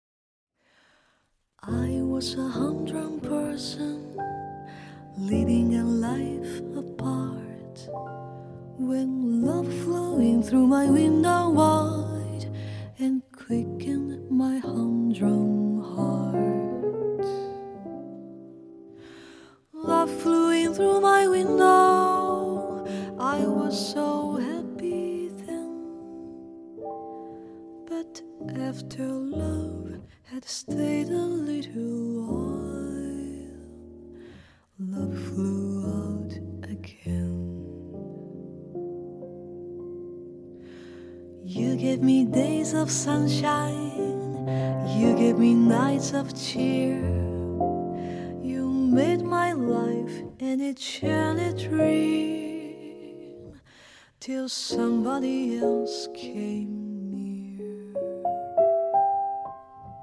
vocal
piano